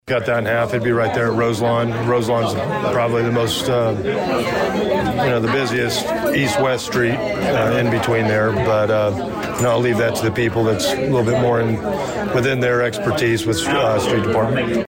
Danville Police Chief Christopher Yates addresses Danville City Council.